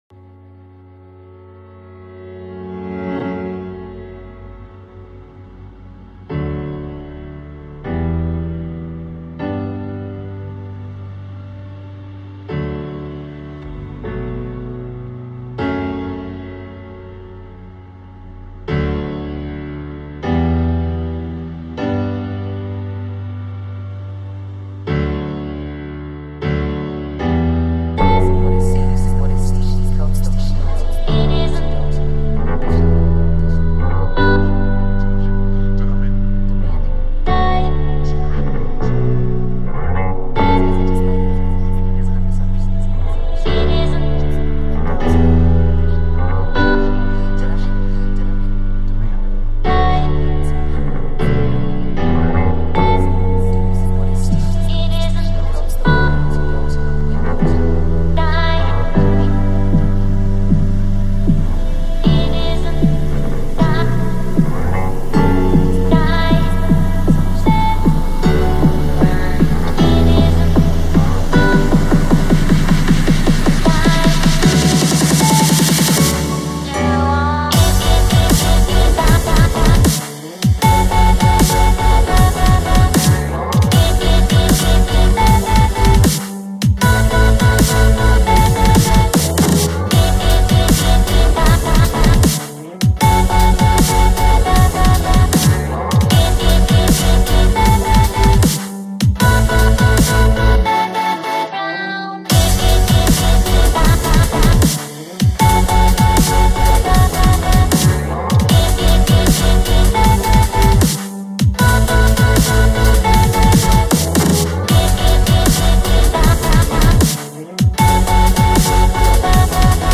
Pretty wicked recording for a first take, no?